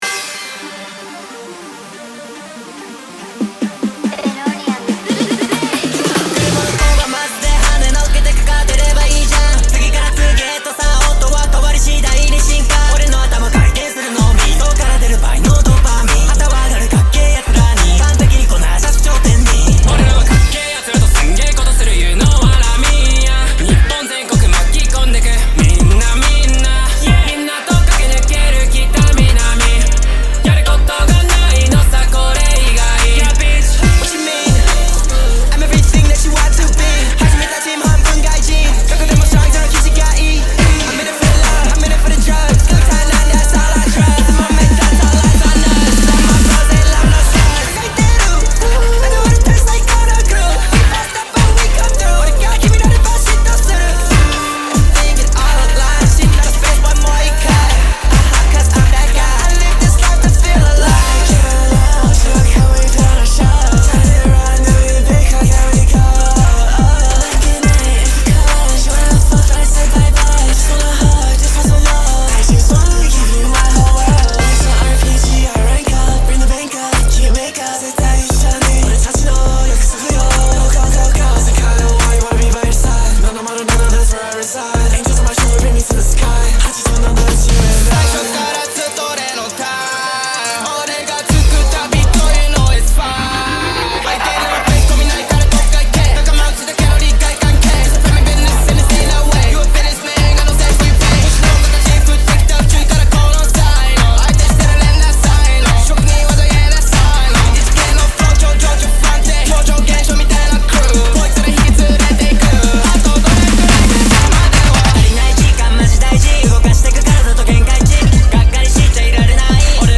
Hip Hop
features soothing atmospheres and tunes